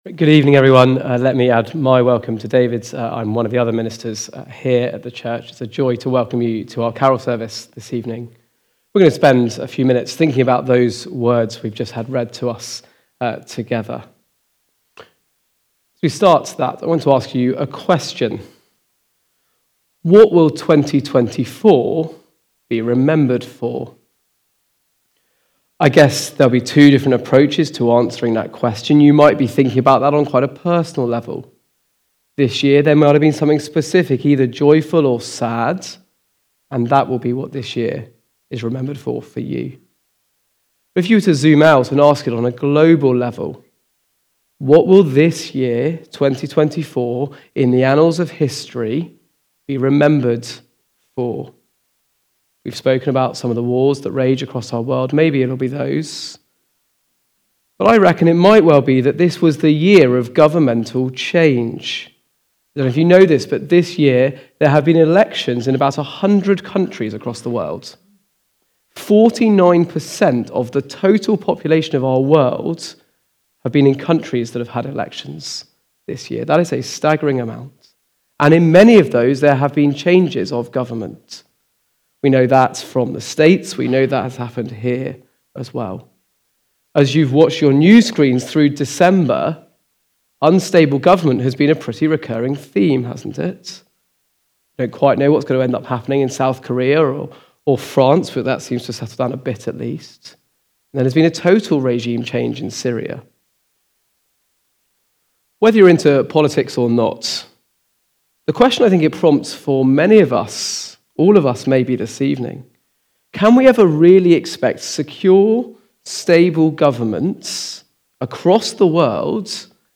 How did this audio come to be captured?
Sermons from Woodstock Road Baptist Church, Oxford, UK